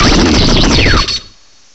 cry_not_vikavolt.aif